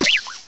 sovereignx/sound/direct_sound_samples/cries/tarountula.aif at master